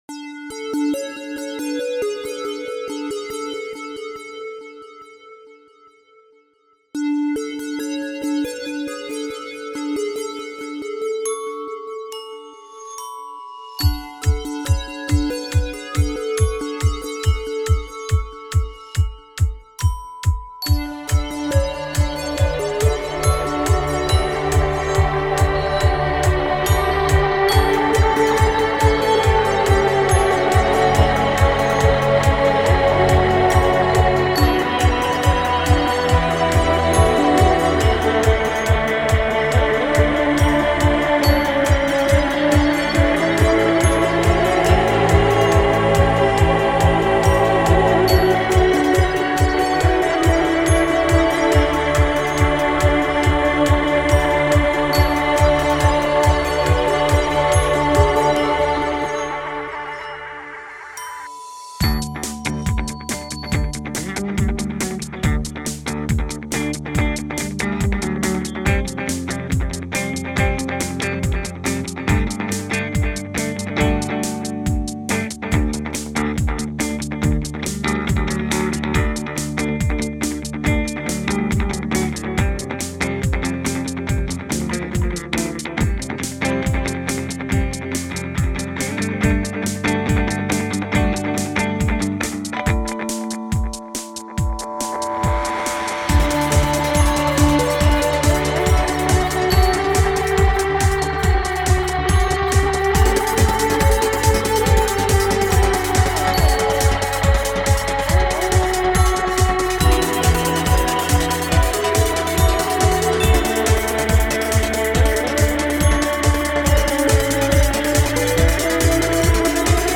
A project that I have been working on for a while now, and I would appreciate some feedback, especially on the drum beat. I feel as though I am lacking in the drums, and I need ideas or thoughts on how to mix them better, and or use Ultrabeat in Logic to my advantage.